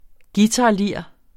Udtale [ ˈgiˌtɑˀˌliɐ̯ˀ ]